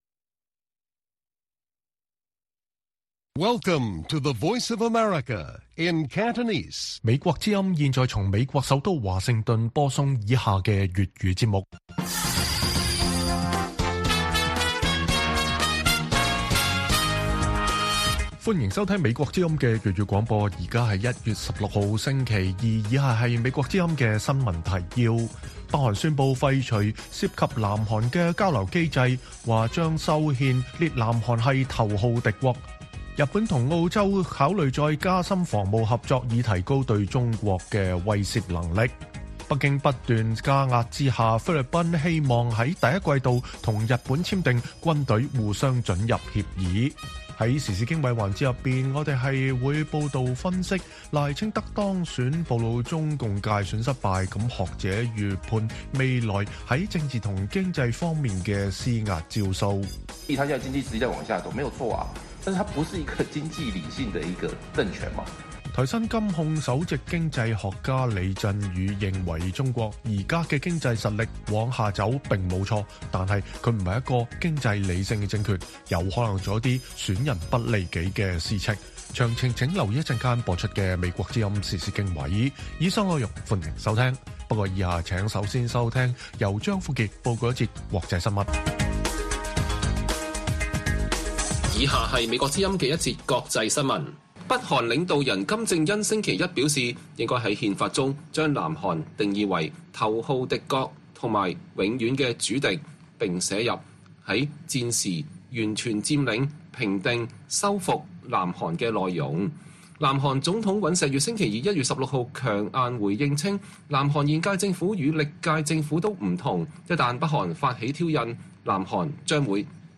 粵語新聞 晚上10-11點: 北韓廢除涉南韓交流機構、又修憲列南韓為頭號敵國